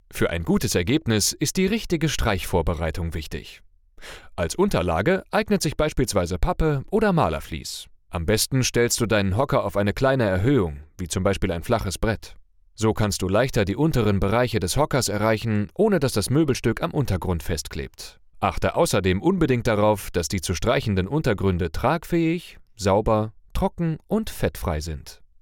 hoher Bariton / mittel - minus , etwa 25 - 40 hell, freundlich, klar, frisch, einfühlsam, emotional, informativ, variabel einsatzbar
Sprechprobe: eLearning (Muttersprache):